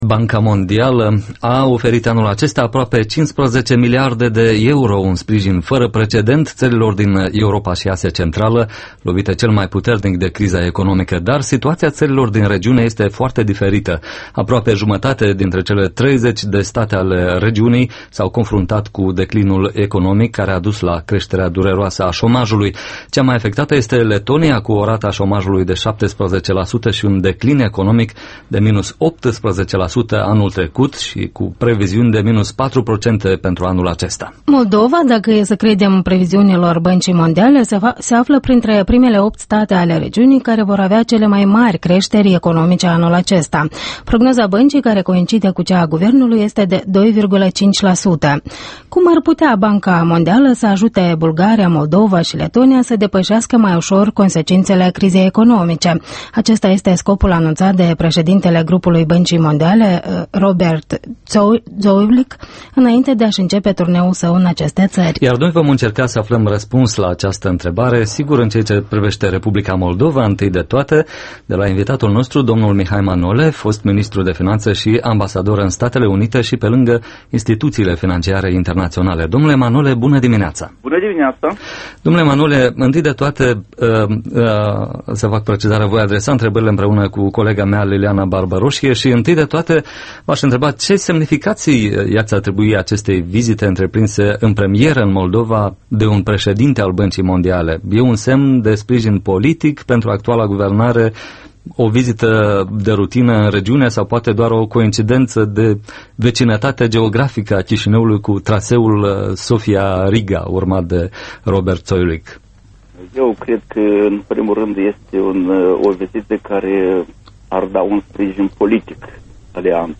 Interviul matinal EL